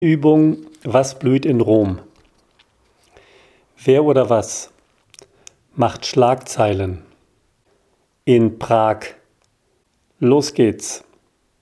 Die ungemischten Audio-Pakete enthalten insgesamt mehr als 4.500 gesprochene Aufgaben.